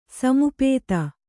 ♪ samupēta